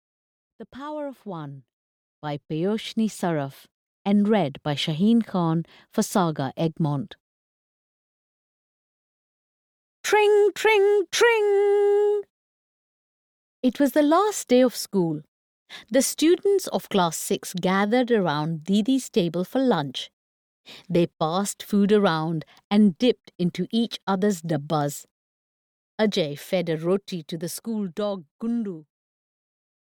The Power of One (EN) audiokniha
Ukázka z knihy